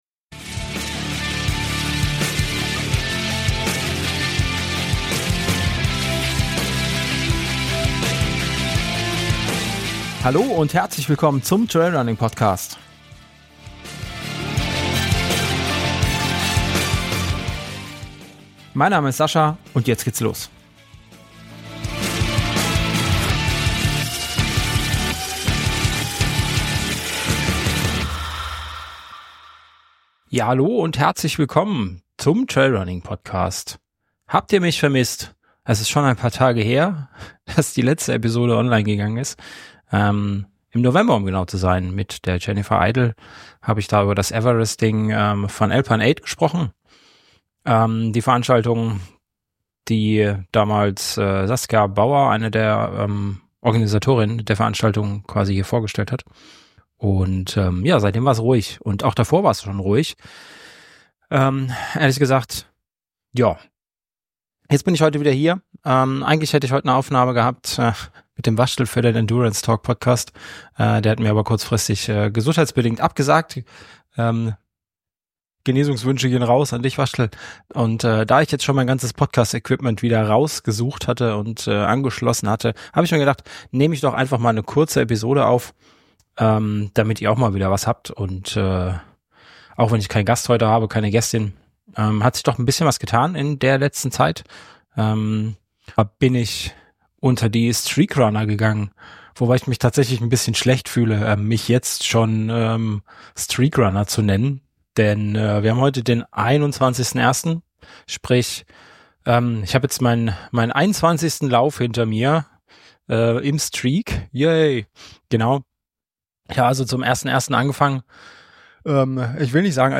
In dieser Solo-Episode erzähle ich euch, warum es in letzter Zeit so still im Podcast war und was sich seitdem bei mir getan hat.